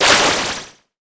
water2.wav